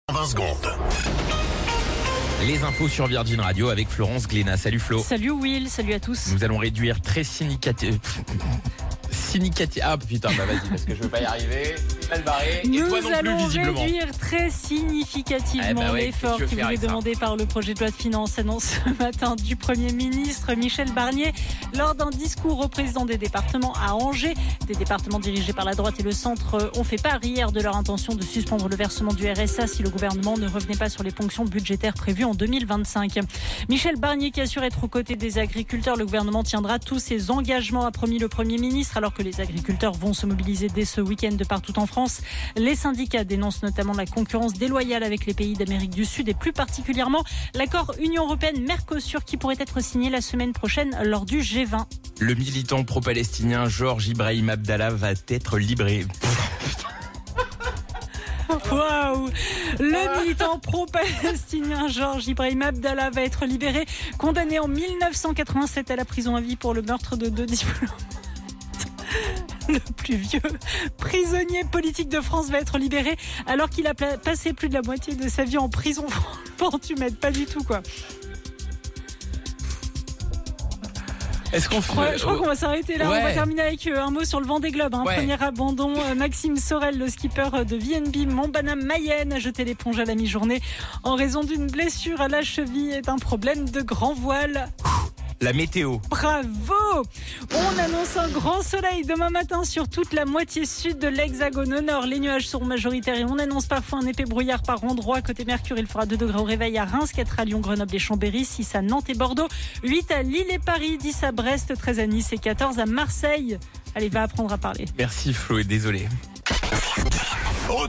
Flash Info National 15 Novembre 2024 Du 15/11/2024 à 17h10 .